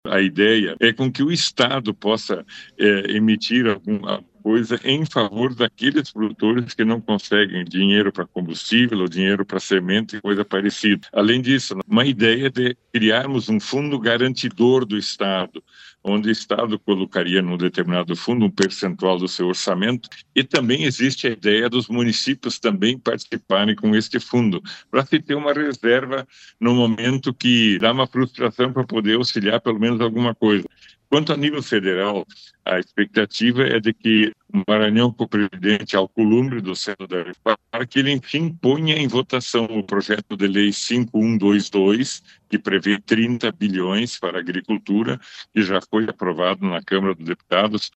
durante entrevista no programa Progresso Rural da RPI